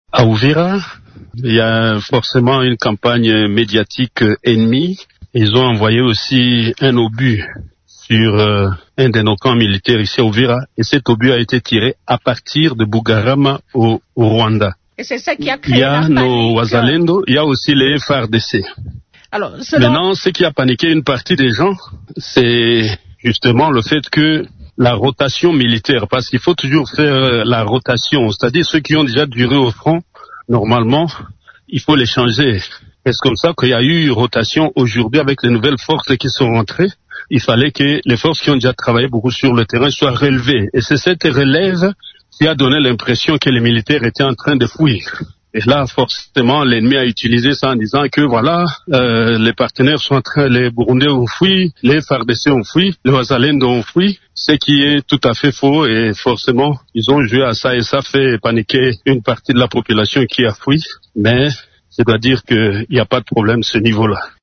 Ce sont des rumeurs totalement infondées répond Jean-Jacques Purusi, le gouverneur du Sud-Kivu: